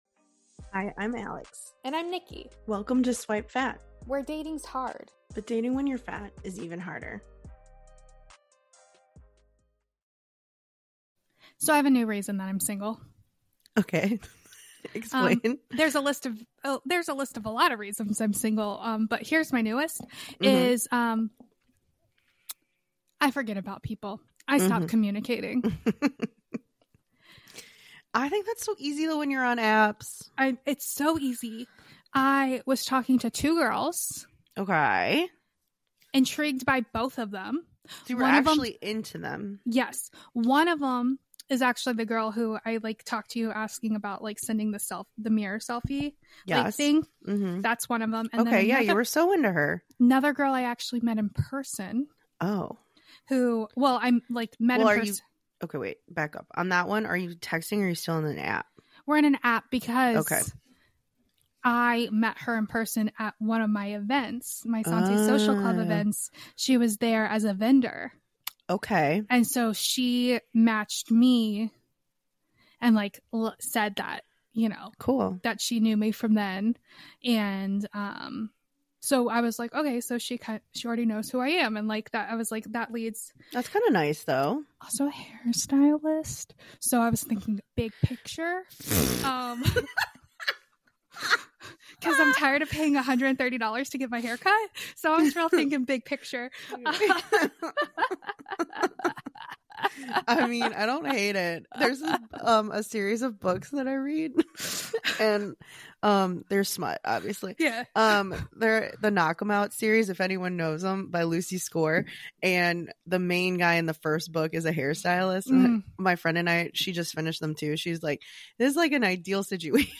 Superb live audience discussion in Sweden